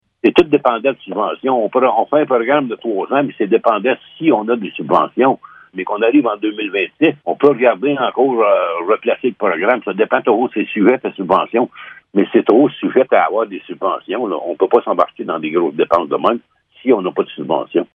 Le maire de Blue Sea, Laurent Fortin, précise que l’avancement de ces projets dépend des subventions octroyées par Québec surtout dans un contexte de resserrement budgétaire :